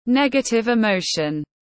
Cảm xúc tiêu cực tiếng anh gọi là negative emotion, phiên âm tiếng anh đọc là /ˈnɛɡətɪv ɪˈmoʊʃən/